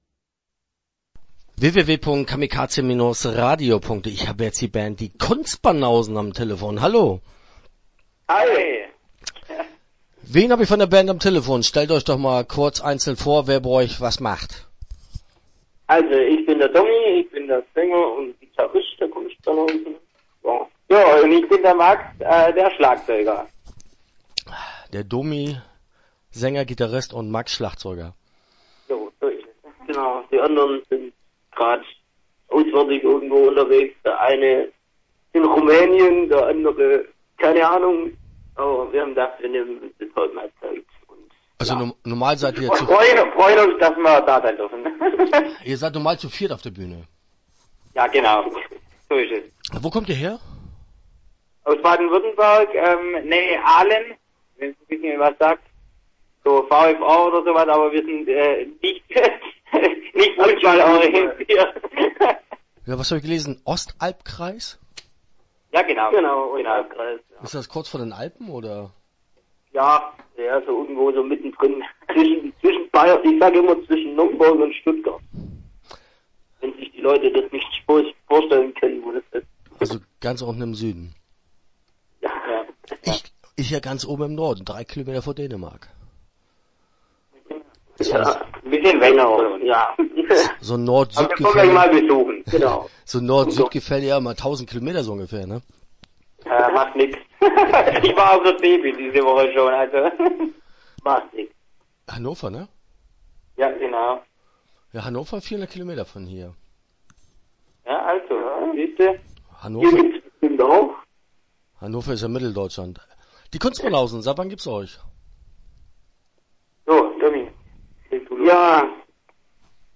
Die Kunstbanausen - Interview Teil 1 (12:56)